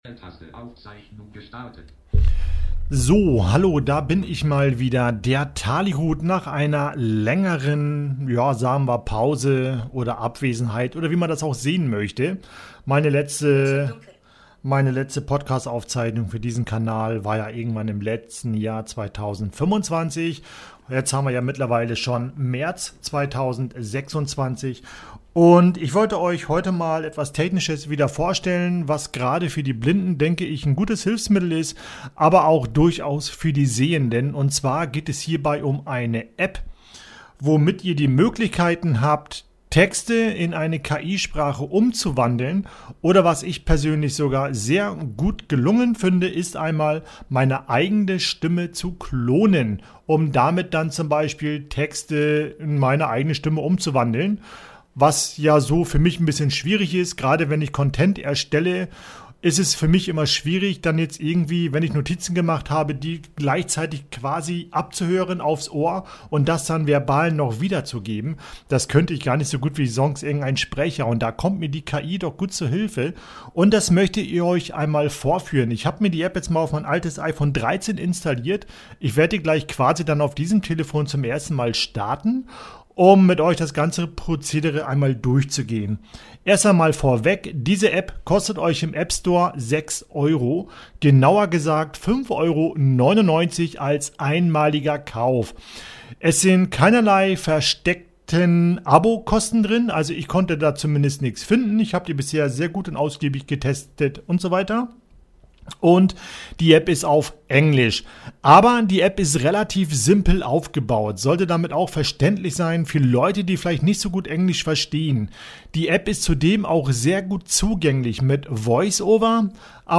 Dort zeige ich euch, wie ihr Texte in eine KI-Stimme umwandeln könnt und wie es möglich ist, eure eigene Stimme kostengünstig als KI-Stimme zu klonen.